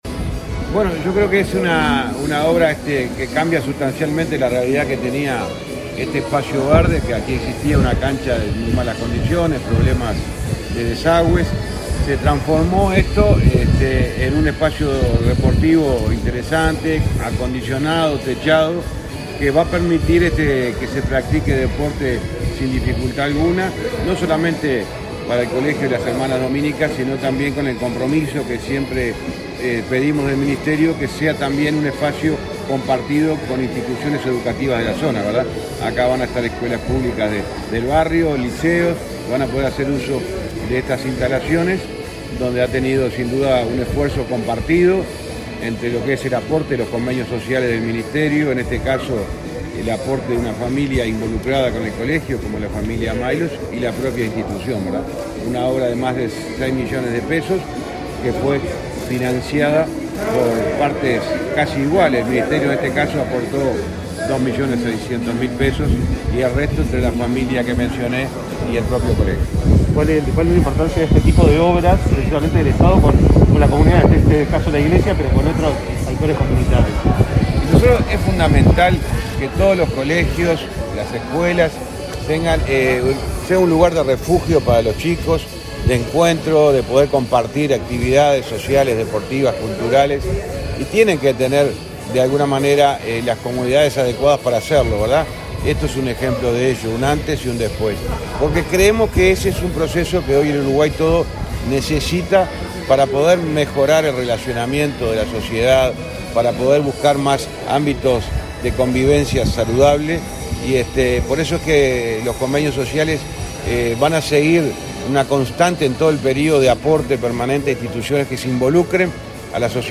Declaraciones a la prensa del ministro de Transporte
Luego, dialogó con la prensa.